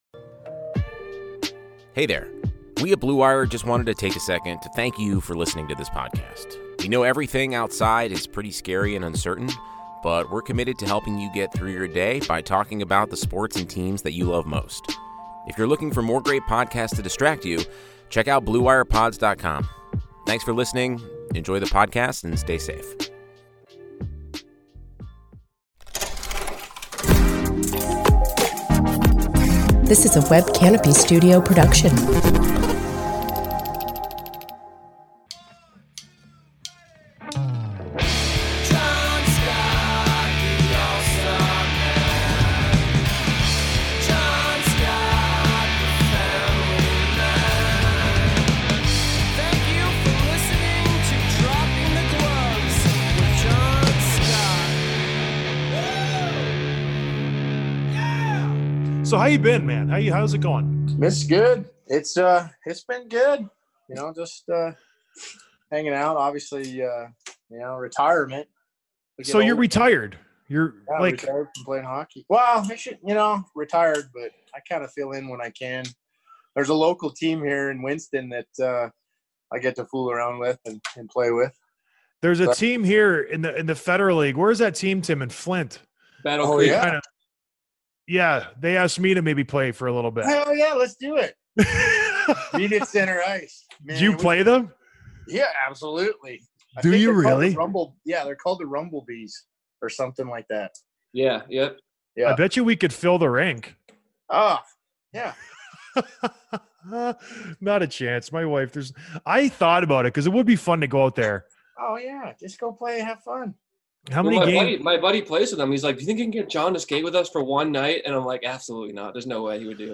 Interview with Enforcer Steve MacIntyre
Despite his fearsome reputation and powerful fists, he's a funny, kind, well-spoken guy who's got some awesome stories both on and off the ice.